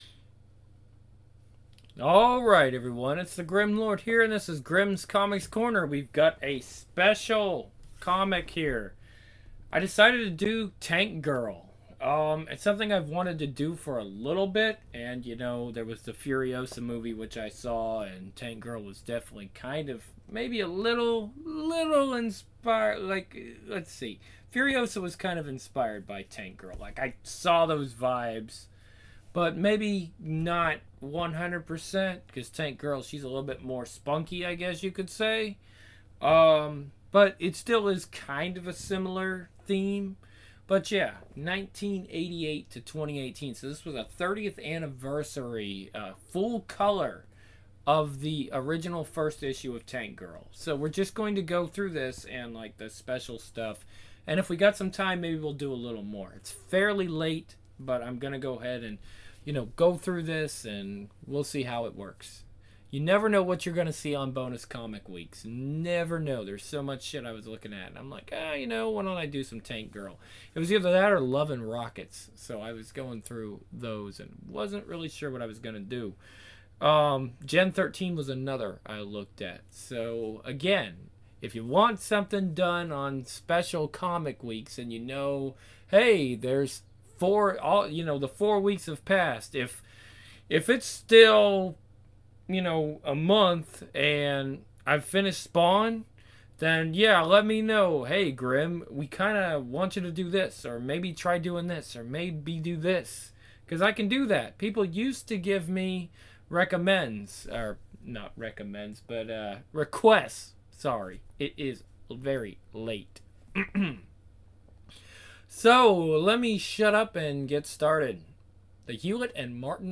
Also, it must have been the time of night, but my brain would not spit out the word "ludicrously" for some reason. Yes, I do know how to say that word, but possibly due to exhaustion, I couldn't say it last night.